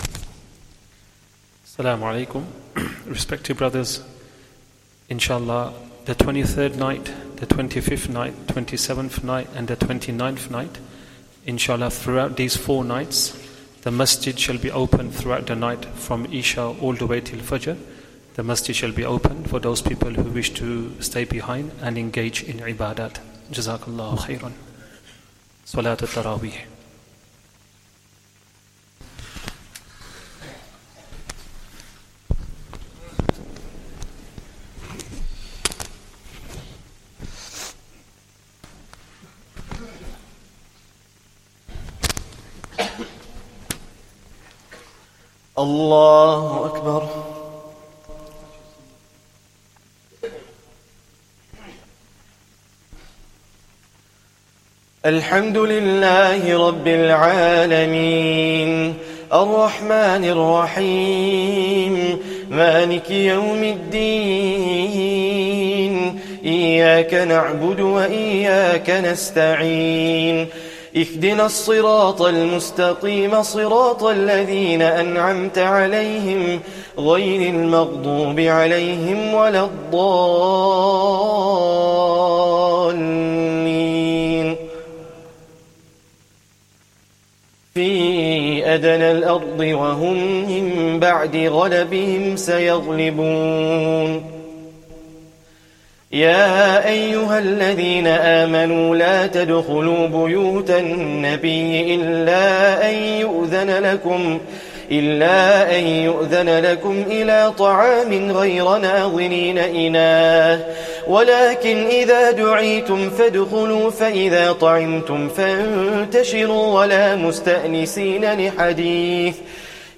Taraweeh Prayer 22nd Ramadhan